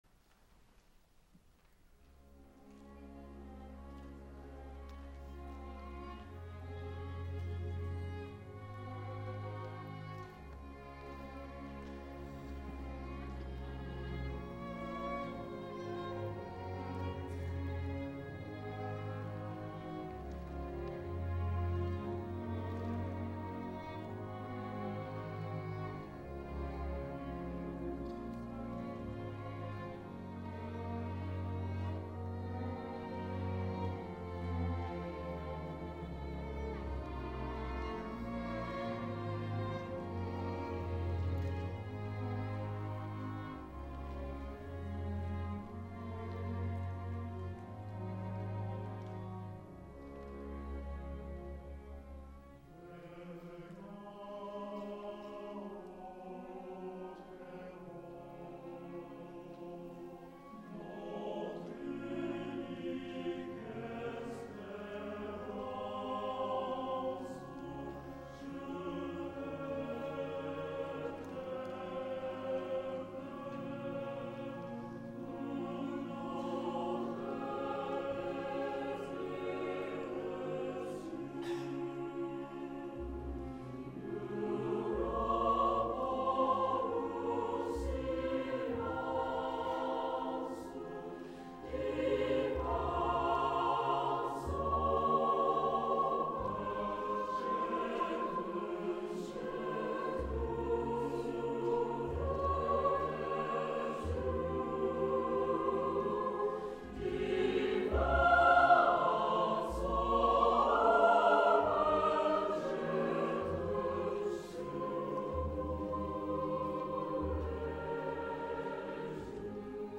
第２６回演奏会音源の公開棚
F-requiem1.mp3 7.3 374 　　客席にセットした自前録音機の
アンコール曲として唱われた